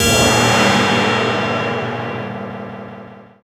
CHINA FX2.wav